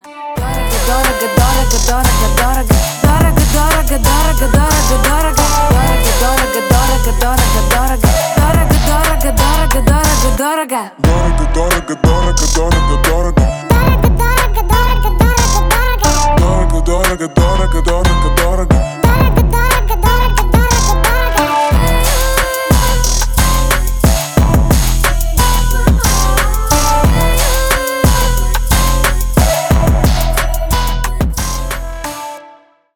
Рэп и Хип Хоп
восточные